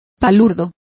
Complete with pronunciation of the translation of boor.